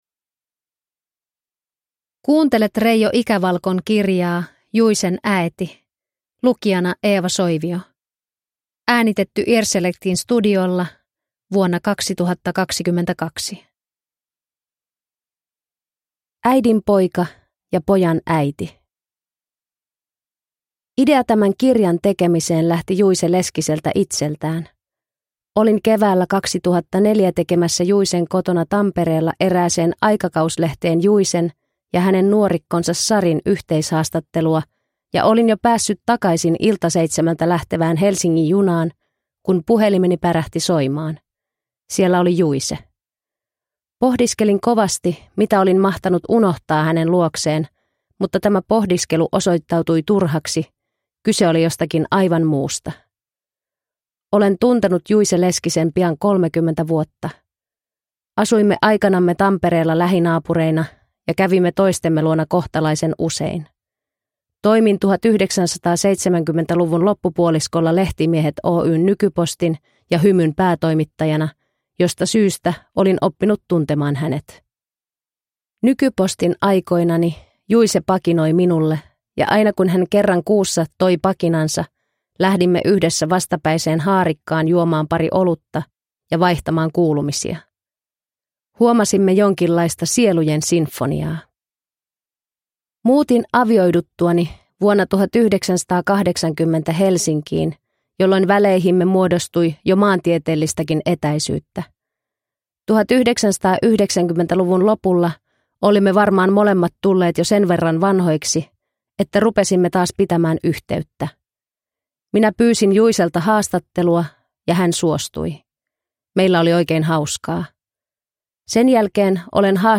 Juicen äeti, Eini Kuikka – Ljudbok – Laddas ner